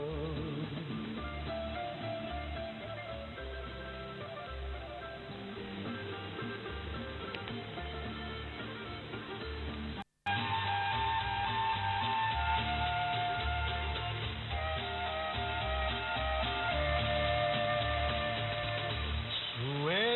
Both devices were operated with the same settings and were supplied with the antenna signal via the antenna distributor of Elad ASA-62.
Second 10 - 20> Winradio G33DDC Excalibur Pro
Pocket mit Surround
AMS-8KHz